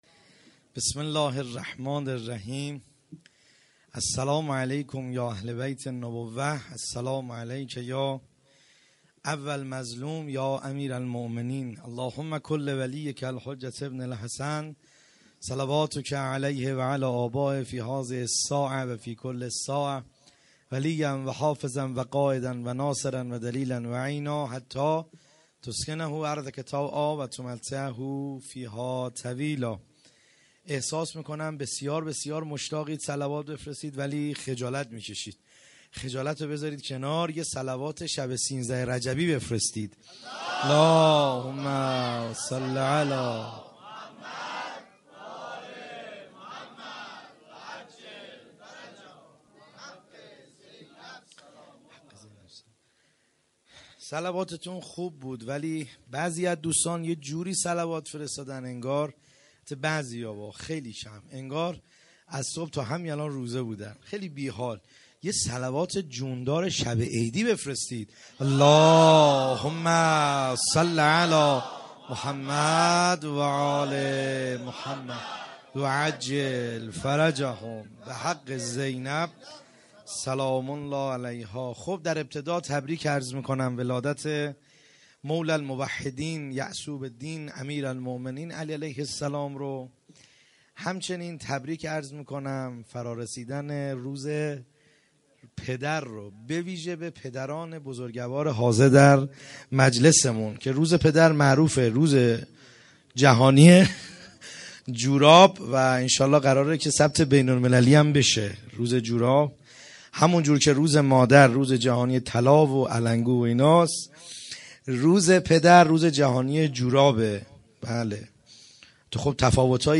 خیمه گاه - بیرق معظم محبین حضرت صاحب الزمان(عج) - سخنرانی | کیفیت عالی